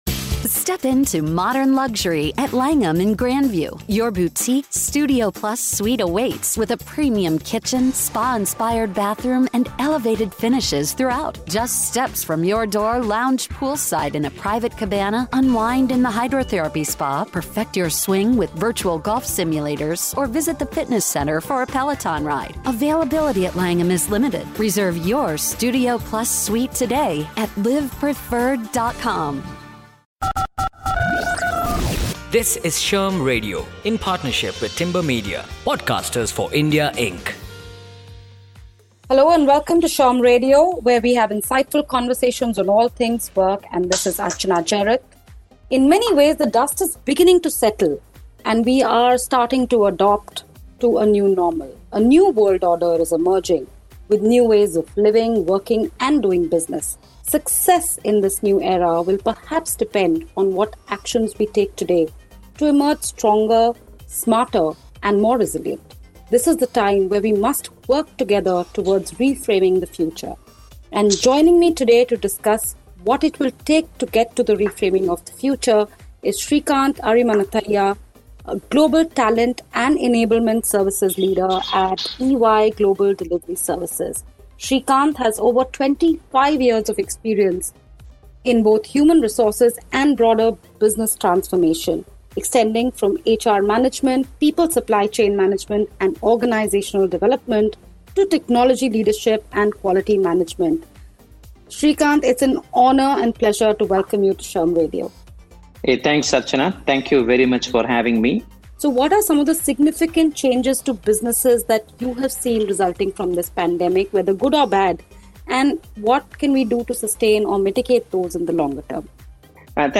Tune in for a radio interview